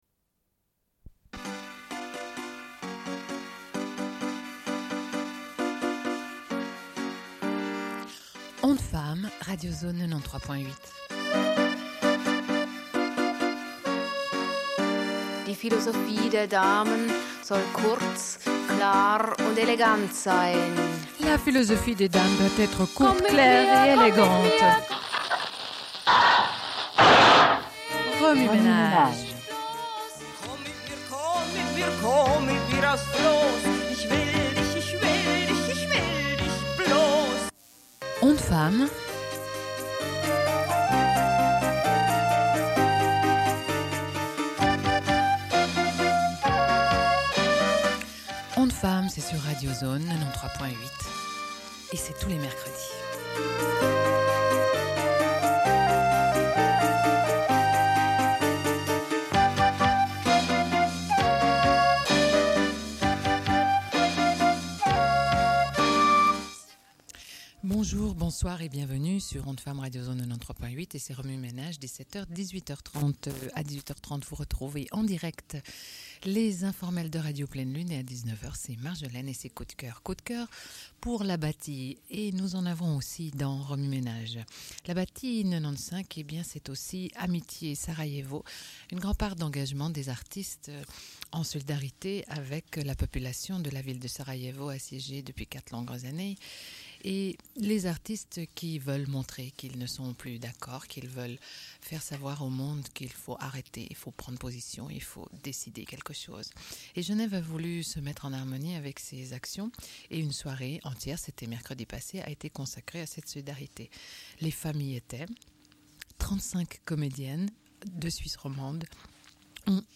Une cassette audio, face A
Genre access points Radio